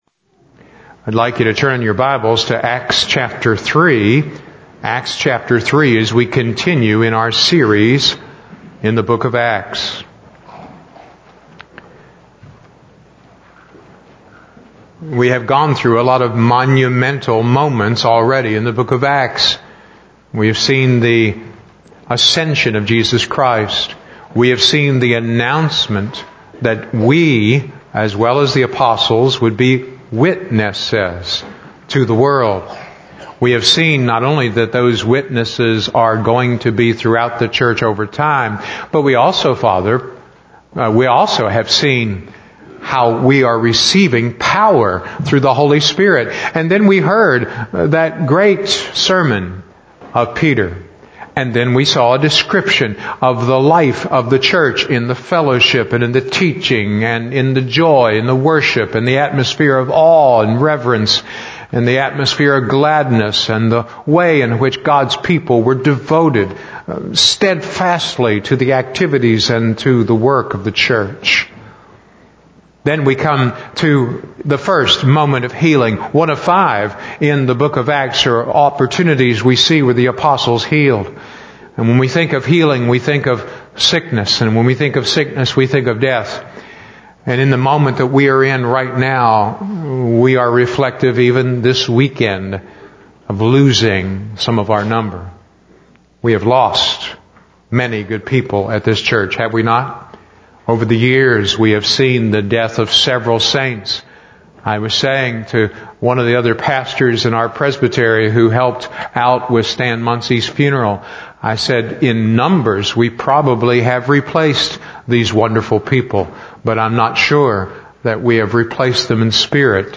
Bible Text: Acts 3:1-10 | Preacher